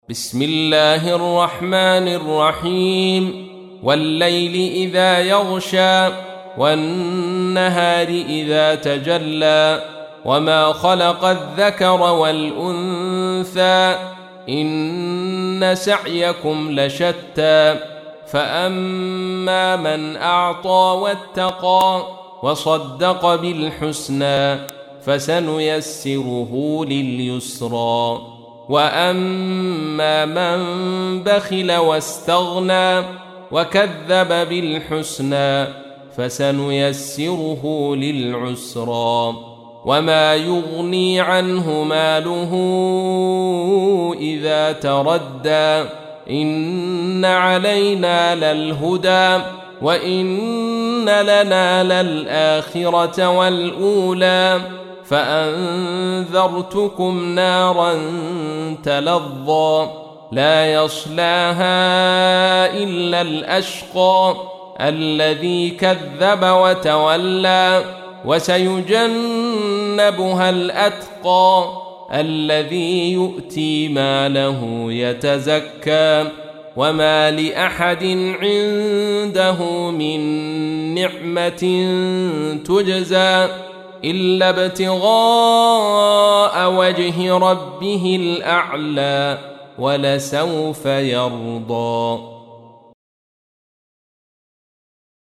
تحميل : 92. سورة الليل / القارئ عبد الرشيد صوفي / القرآن الكريم / موقع يا حسين